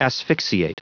Prononciation du mot asphyxiate en anglais (fichier audio)
Prononciation du mot : asphyxiate